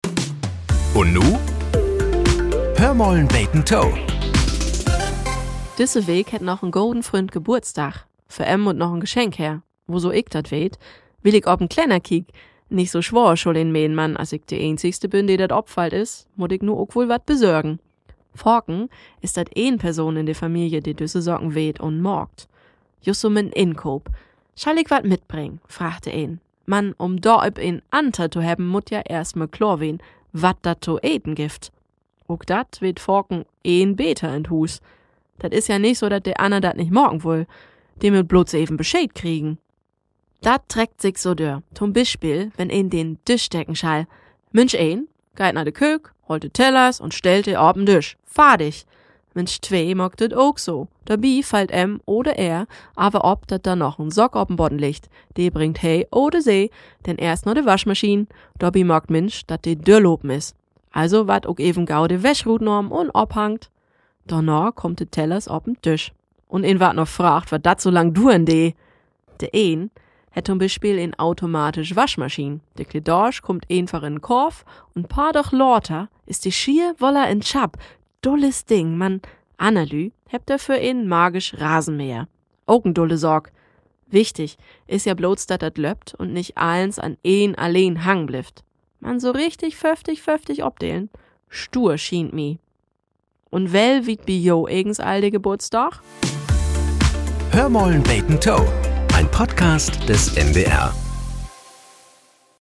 Nachrichten - 21.05.2025